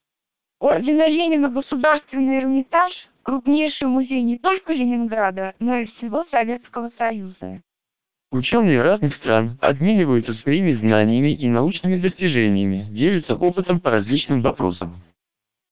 Experts haven't found significant difference in sounding between SPR 1200 and MELPe 1200 vocoders.
You can play and listen short samples of the source speech as well as the speech processed by these vocoders for any of 20 languages, using links in the table below.